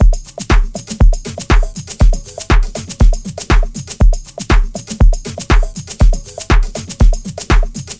标签： 120 bpm Electro Loops Drum Loops 1.35 MB wav Key : Unknown
声道立体声